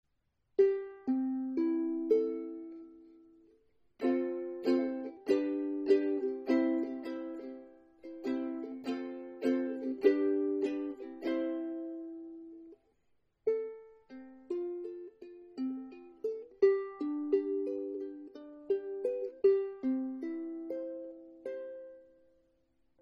Meine Soundbeispiele sind alle am gleichen Tag mit einem Zoom H2 aufgenommen.
Gespielt habe ich nur ein paar Akkorde.
Korpus massiv Akazie,  Worth-Saiten
-Klanglich allgemein gefällt mir die Koa Pili Koko am besten (so stell ich mir vor muß eine Uke klingen)